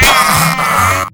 sentry_damage3.wav